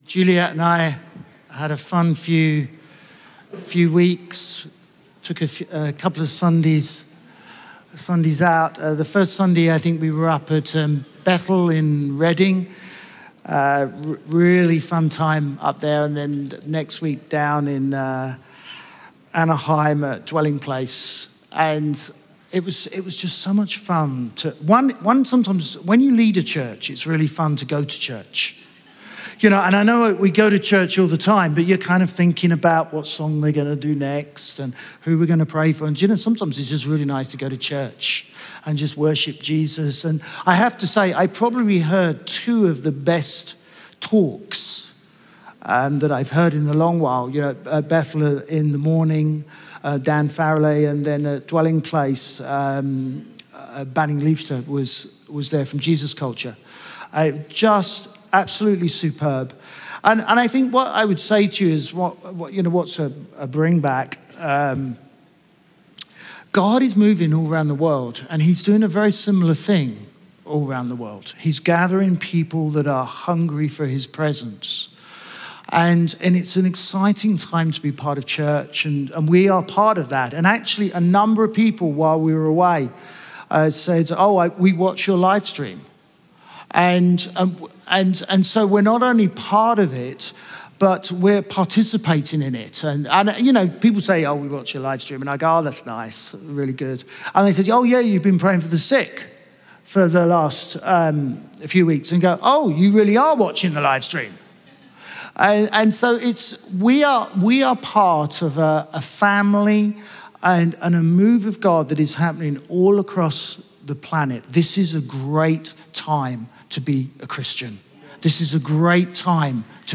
Chroma Church - Sunday Sermon Jericho – This Is How We Fight Our Battles May 05 2023 | 00:37:29 1x 00:00 / 00:37:29 Subscribe Share RSS Feed Share Link Embed